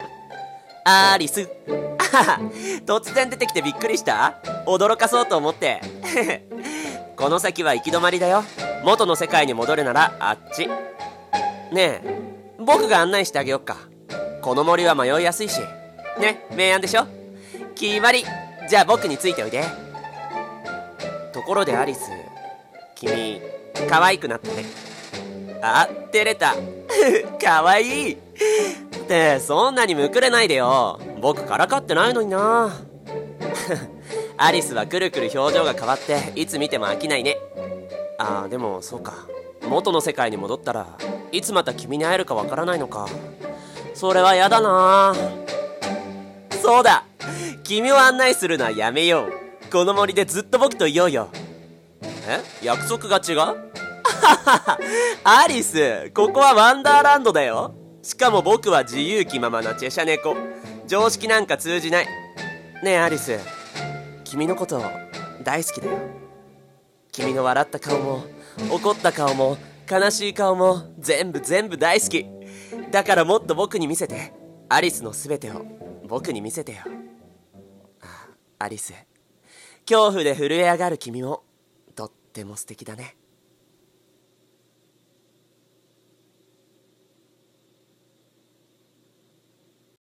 狂った国のアリス 〜猫の場合〜【一人声劇台本】